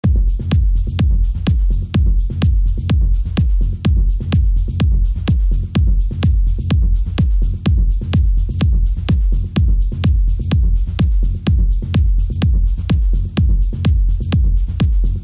Technobeat